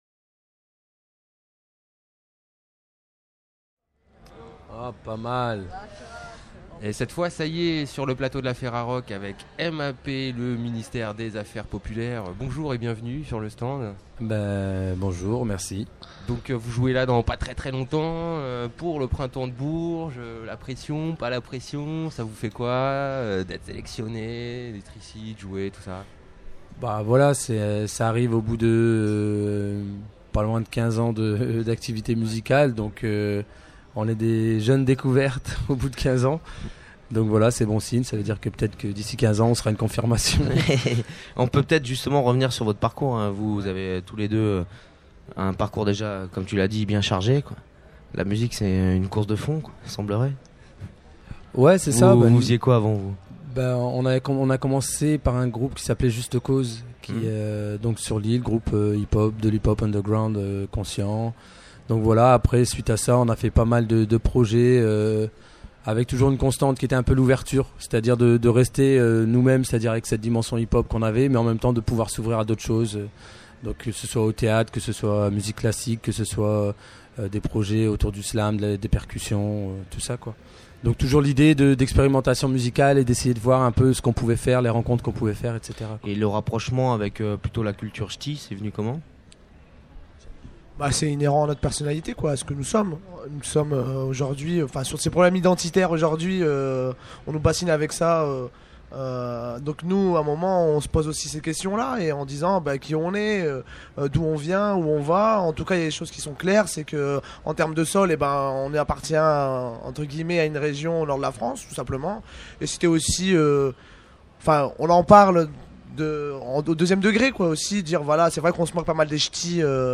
Le M.A.P., le Ministère des Affaires Populaires Festival du Printemps de Bourges 2006 : 40 Interviews à écouter !